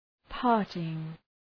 Προφορά
{‘pɑ:rtıŋ}